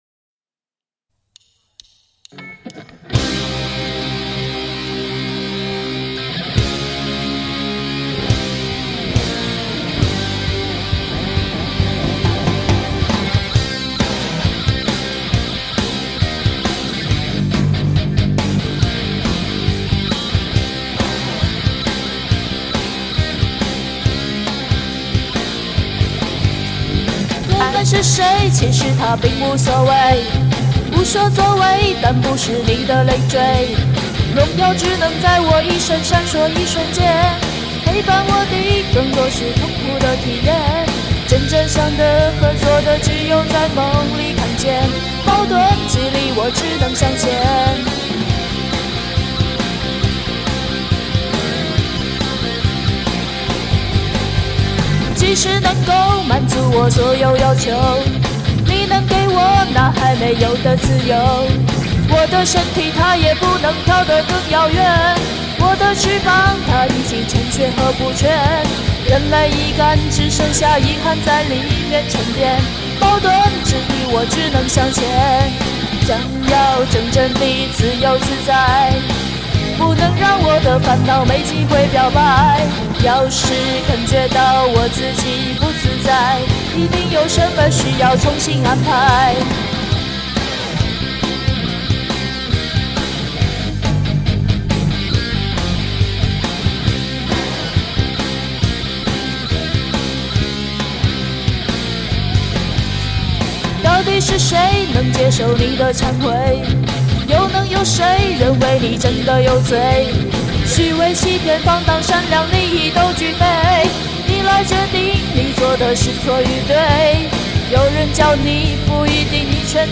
已经很用力了，结果还是软绵绵的感觉哈哈哈。